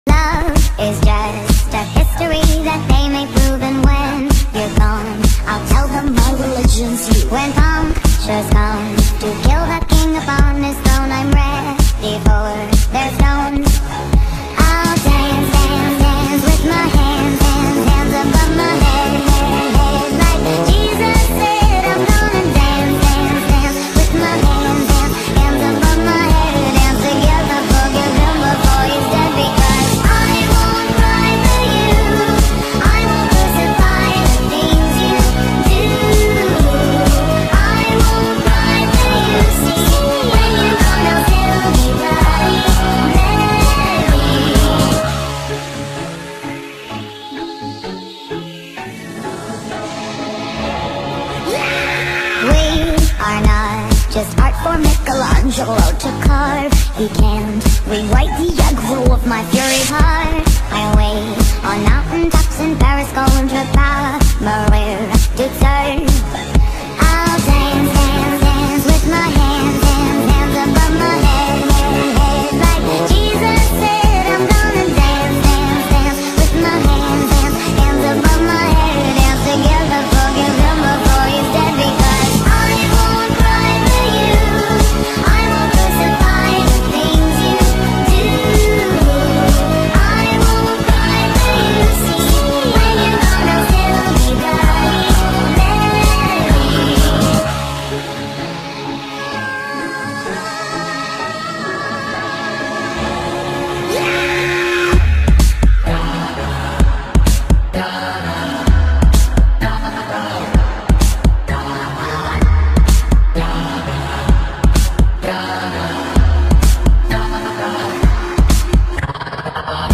BPM128-128
Audio QualityCut From Video